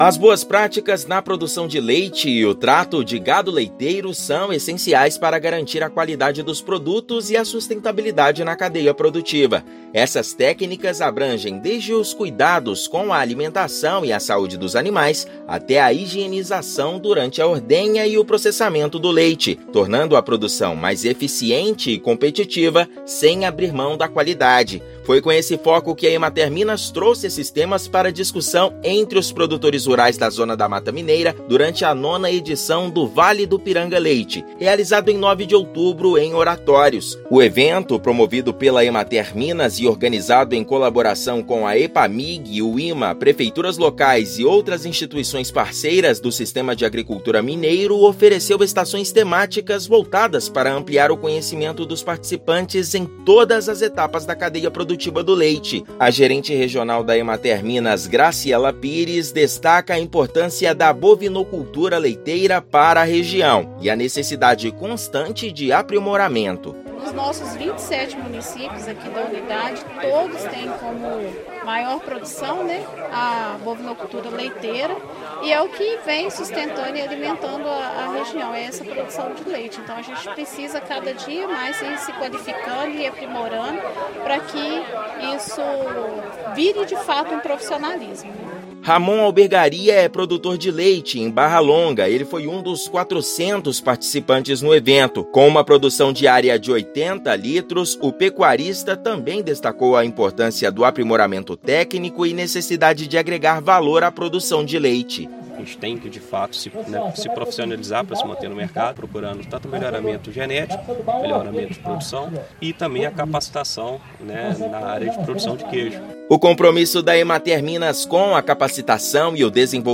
Ações da Emater-MG incluem capacitação dos produtores e incentivo a adoção de técnicas avançadas no manejo do gado e do leite, promovendo a qualidade do queijo e a sustentabilidade. Ouça matéria de rádio.